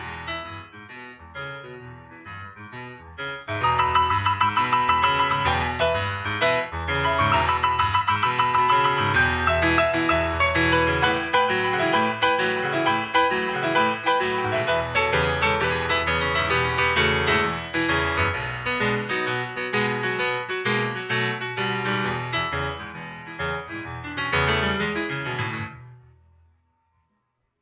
To hear just the isolated piano track of the piano solo,